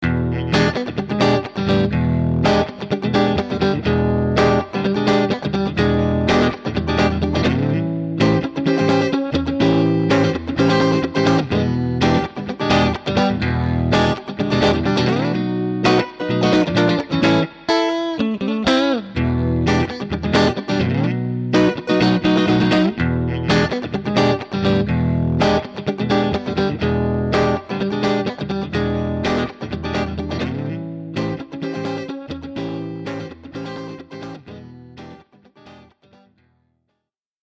The performance of this circuit is very good (at least to my ears).
Rock n' roll sample